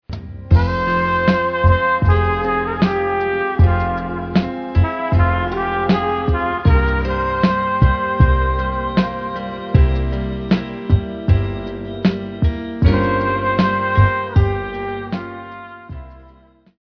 italienne